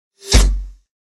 Target Strike Sound Effect
Powerful target hit sound effect with swoosh. Perfect for big arrows or heavy objects striking a target.
Target-strike-sound-effect.mp3